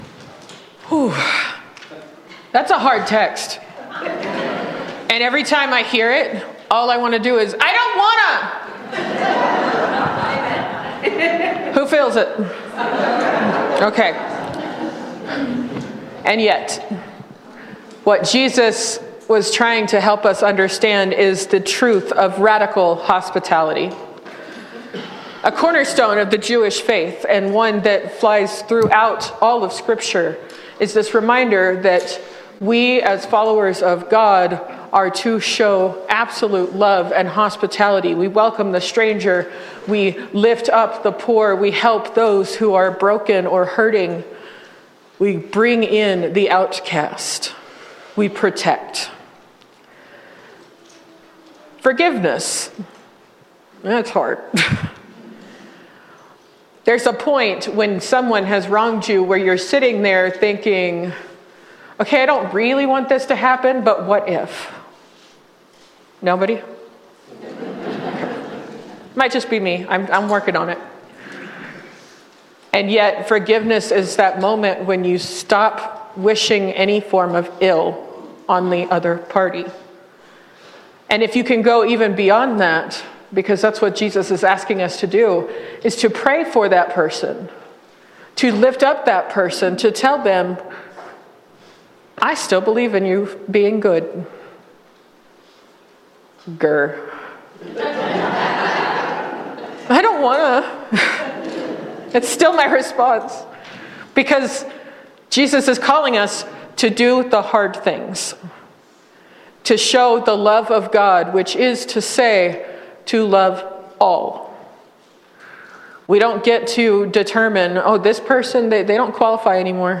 Sermons – Page 3 – All Saints Lutheran Church, ELCA